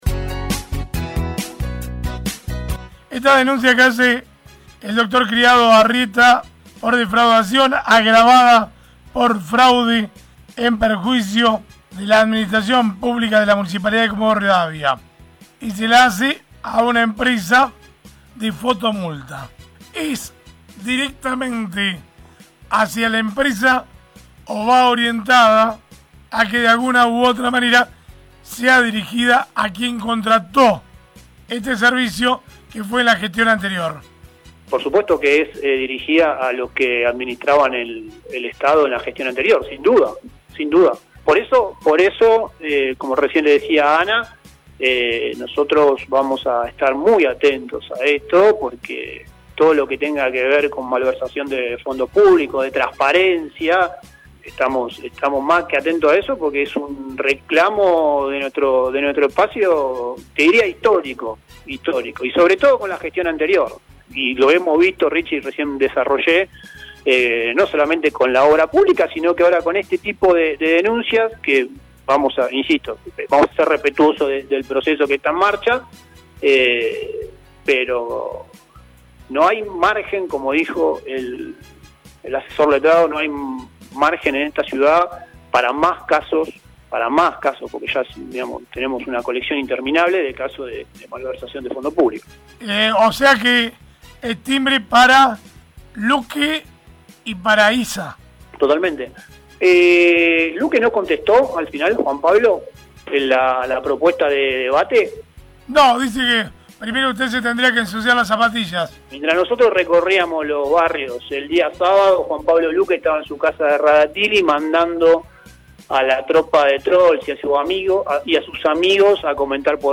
El Concejal por el bloque Despierta Comodoro dijo a través de los micrófonos de Radiovision que “todos vamos a estar muy atentos con la denuncia por fotomultas que se le hizo a la gestión anterior”.